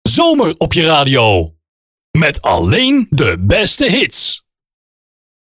Gesproken jingle
Zonder Soundeffects
01 - Zomer op je radio - Met alleen de beste hits (Voice Only).mp3